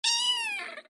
Звуки котят
• Качество: высокое
Котята издают самые милые звуки: от нежного мяуканья до успокаивающего мурлыканья.